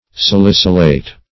Salicylate \Sal"i*cyl`ate\ (-[asl]t)